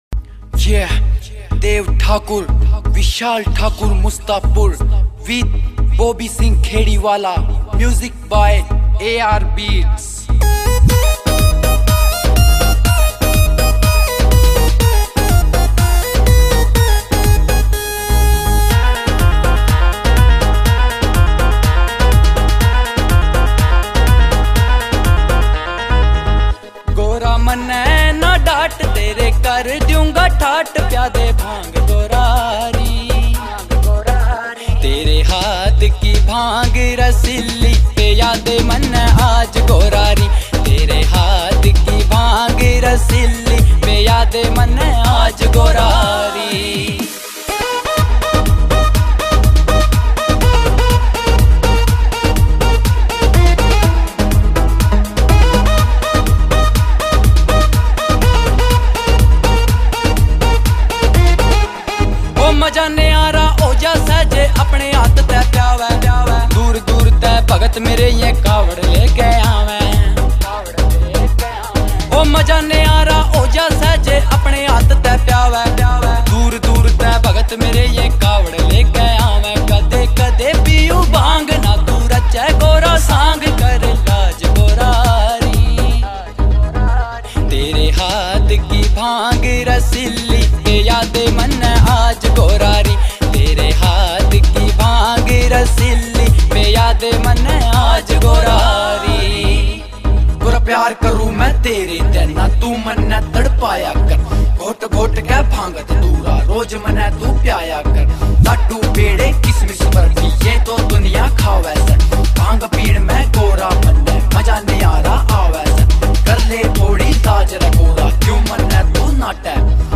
Devotional (Bhajan)